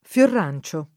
fLorr#n©o] s. m.; pl. ‑ci — una pianta erbacea, altrimenti detta calendola, con fiore arancione; un piccolo uccello con ciuffo arancione, detto più spesso fiorrancino [fLorran©&no] o anche fiorancino [fLoran©&no]